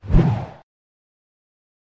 Sound Effects
Flappy Bird Slashing